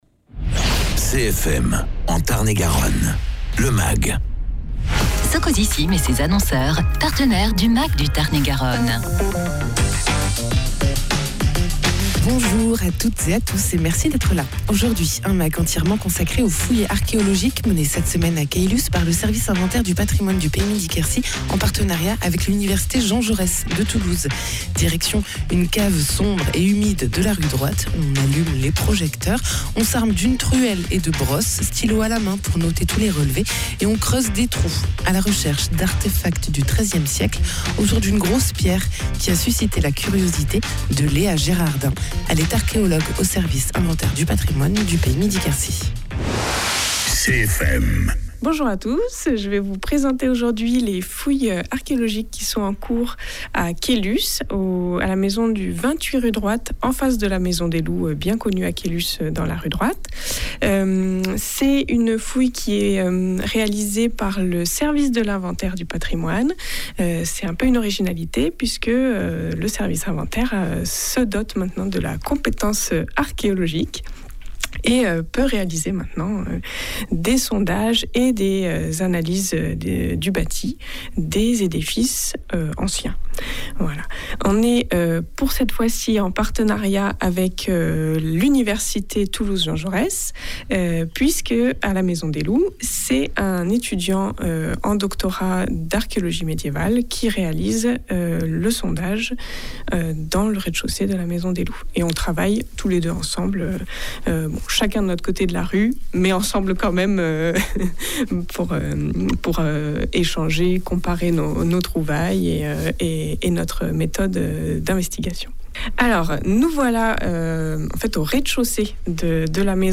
En partenariat avec l’université Jean Jaurès de Toulouse, des fouilles archéologiques sont actuellement menées à Caylus, par le service inventaire du patrimoine du PETR Midi Quercy. Reportage sur place.